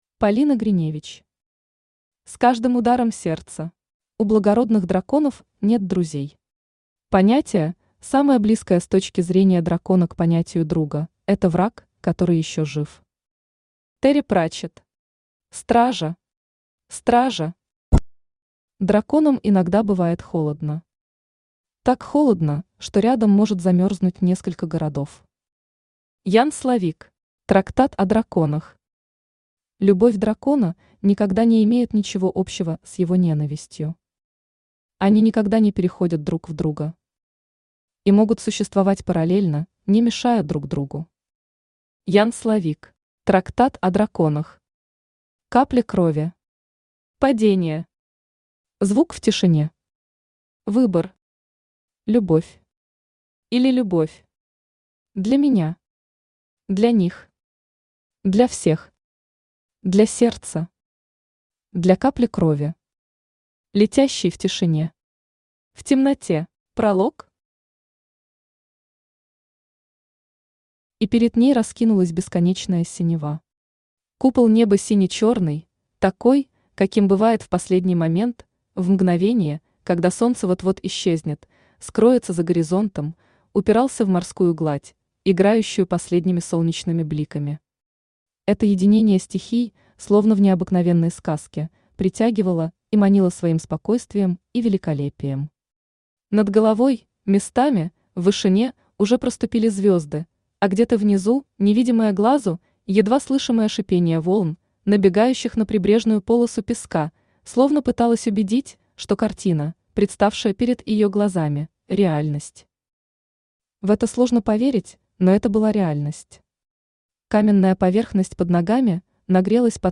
Аудиокнига С каждым ударом сердца | Библиотека аудиокниг
Aудиокнига С каждым ударом сердца Автор Полина Гриневич Читает аудиокнигу Авточтец ЛитРес.